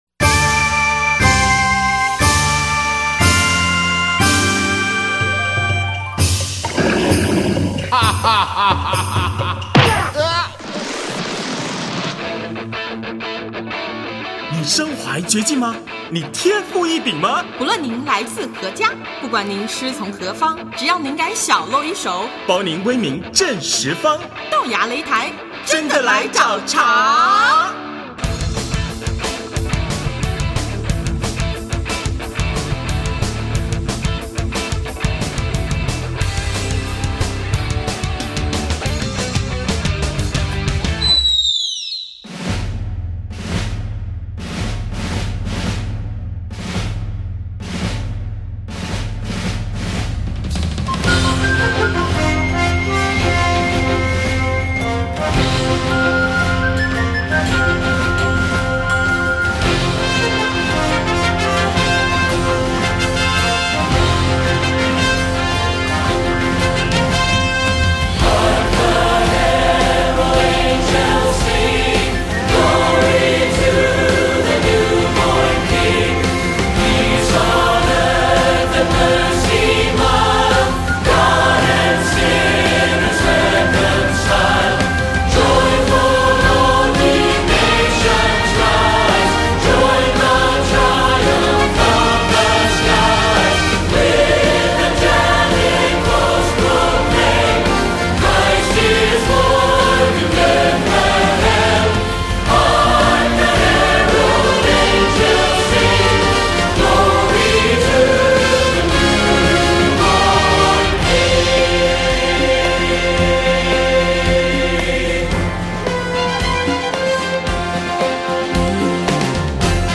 她如丝绸般的中音，被日本公认为爵士女伶新指标。